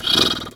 pgs/Assets/Audio/Animal_Impersonations/horse_2_breath_04.wav at master
horse_2_breath_04.wav